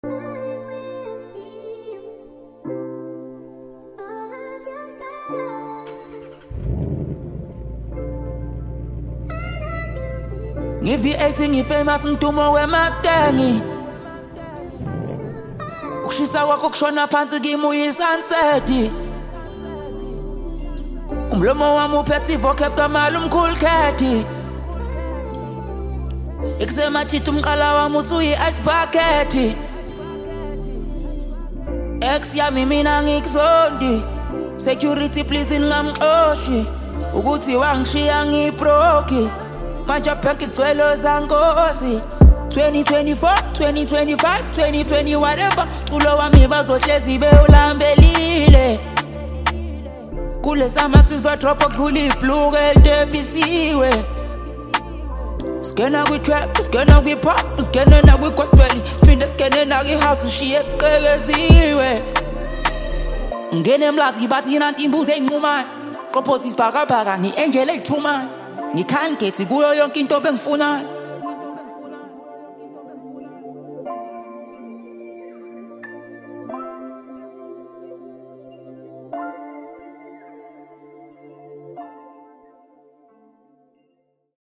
Home » Amapiano » DJ Mix